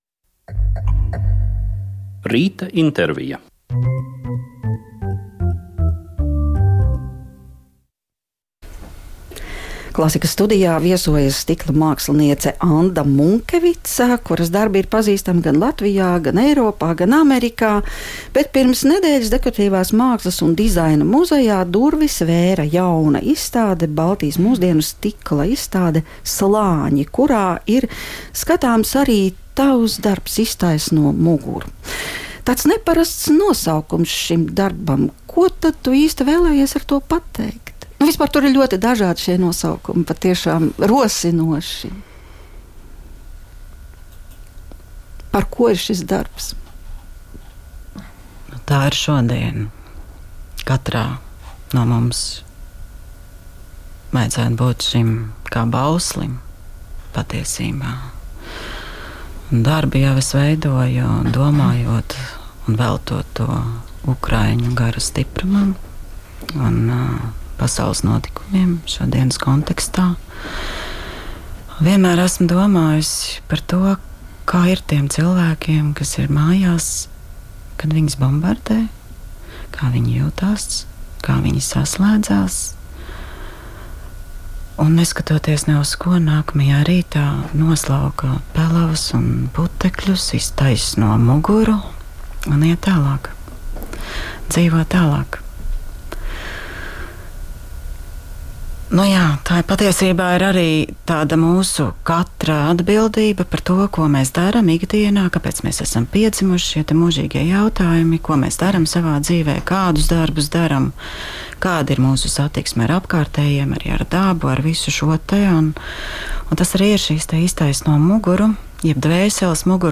"Mākslas vingrošana" – tā ir profesionāļu saruna, kurā tiek aplūkotas būtiskas vizuālās mākslas tēmas, kas skar gan aktuālos jautājumus – izstādes, procesus, dažādas problēmas, gan to sasaiste ar mākslas mantojumu.